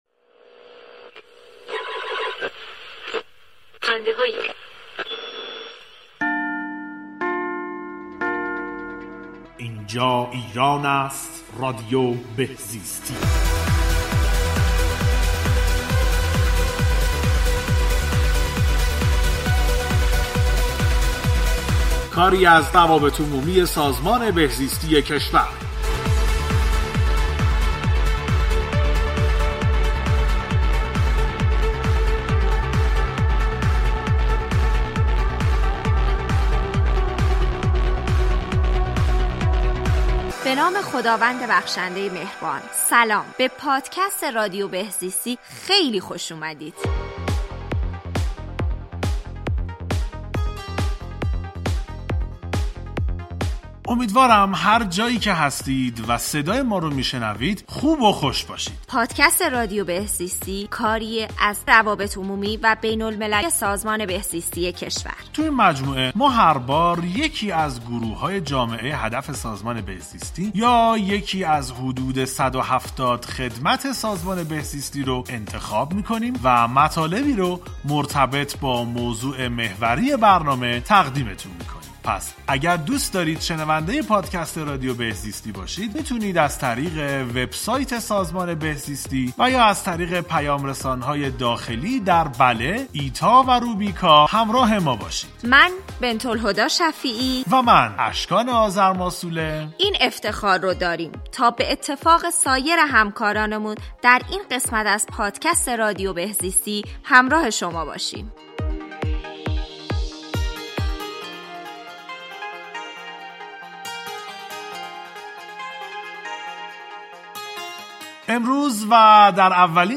در این قسمت به موضوع سالمندی در ایران پرداخته ایم که با هم گوش می کنیم. گفتگو